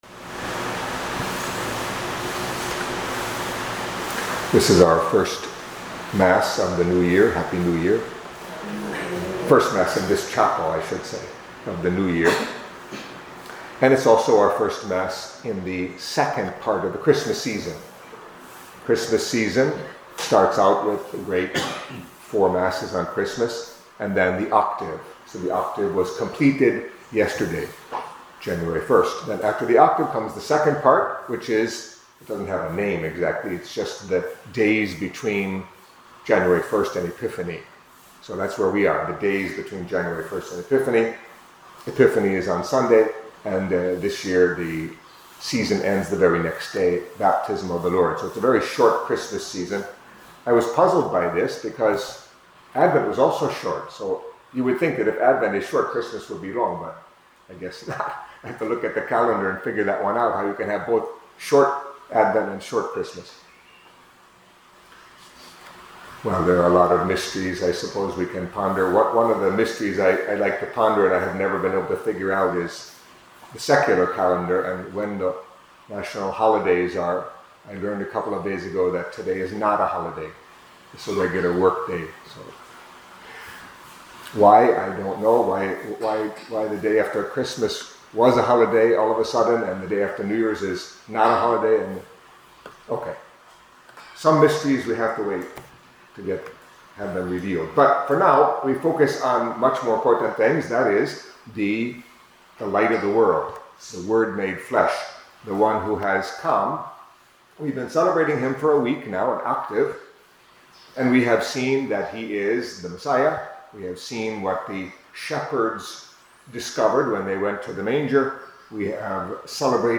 Catholic Mass homily for Tuesday